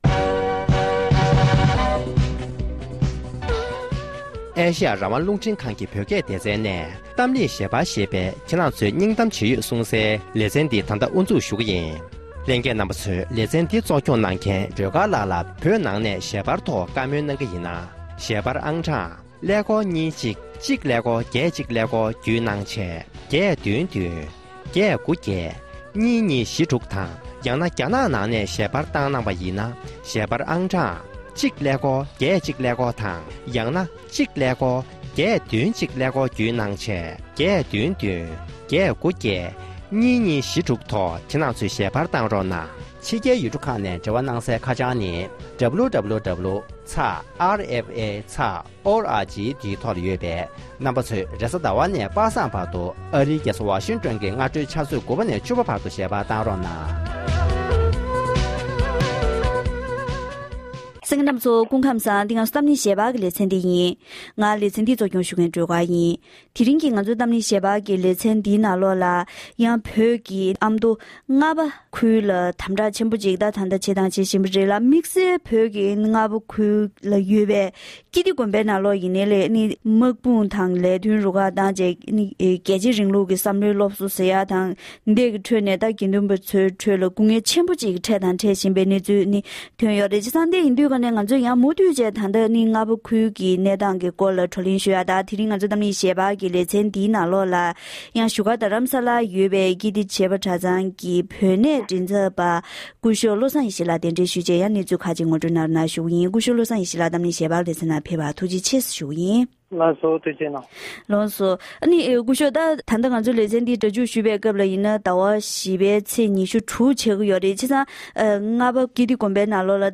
གཏམ་གླེང་ཞལ་པར